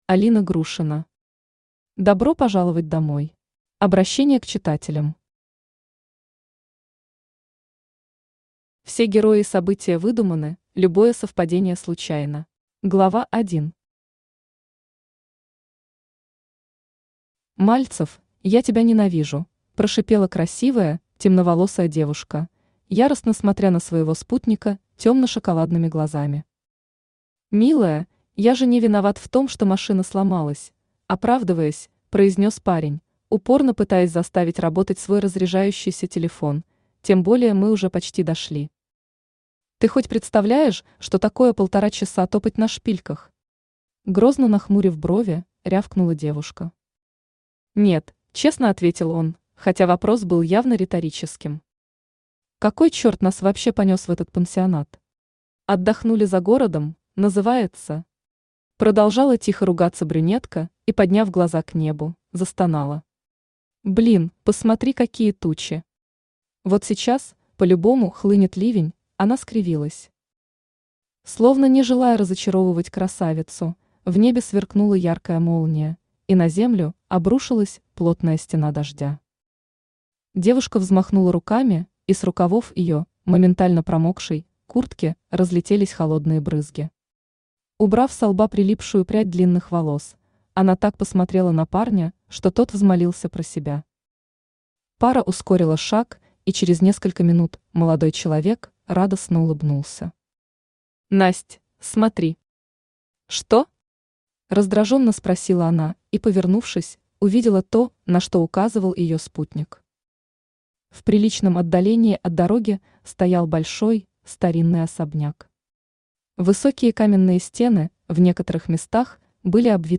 Aудиокнига Добро пожаловать домой… Автор Алина Владимировна Грушина Читает аудиокнигу Авточтец ЛитРес.